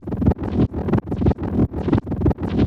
Index of /musicradar/rhythmic-inspiration-samples/90bpm
RI_ArpegiFex_90-05.wav